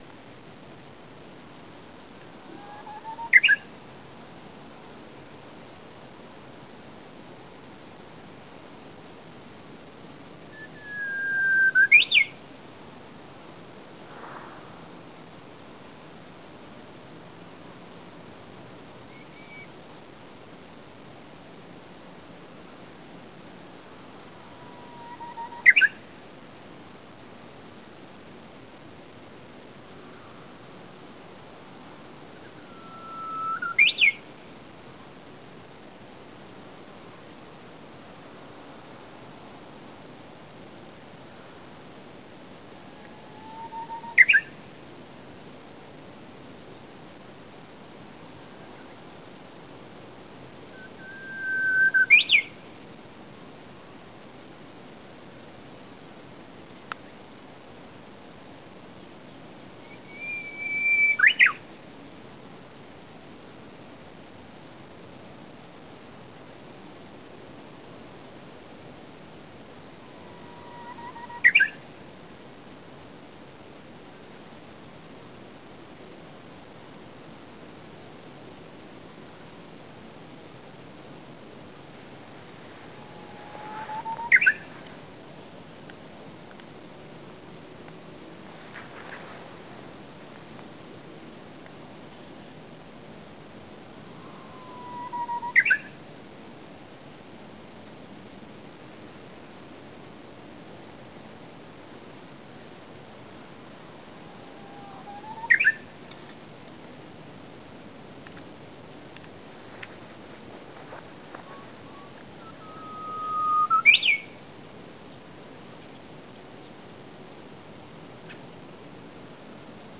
uguisu.wav